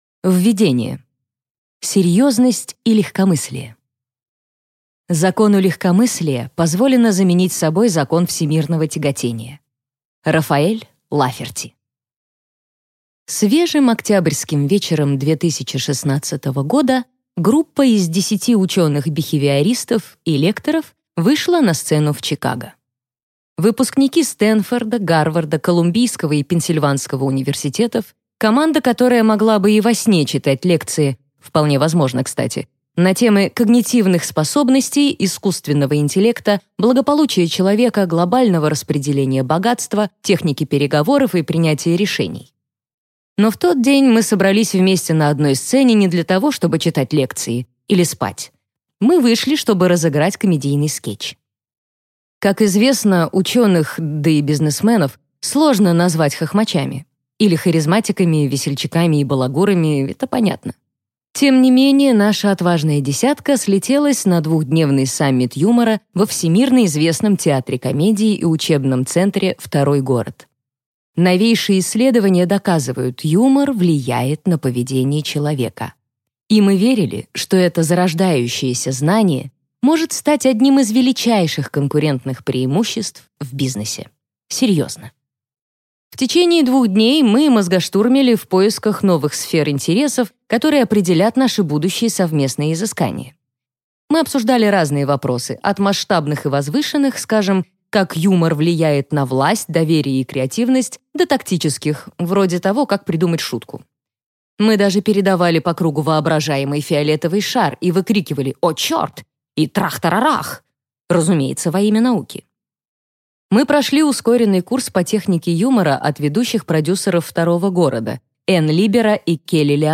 Аудиокнига Юмор – это серьезно. Ваше секретное оружие в бизнесе и жизни | Библиотека аудиокниг